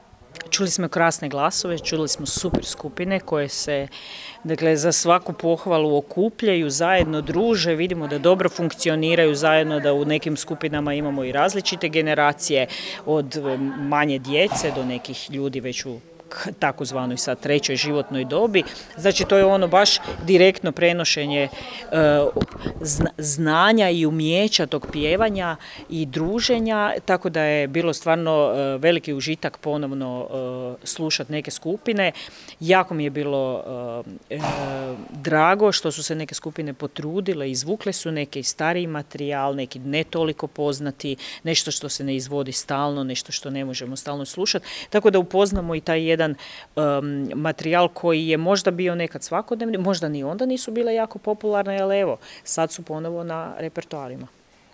U organizaciji Zajednice kulturno-umjetničkih udruga SMŽ i uz potporu Ministarstva kulture i medija i Sisačko-moslavačke županije u Županijskom centru s kongresnom dvoranom u Sisku održana je Smotra malih vokalnih sastava SMŽ.